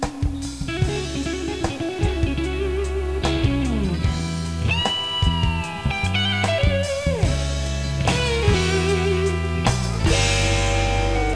8 bit mono
guitar
drums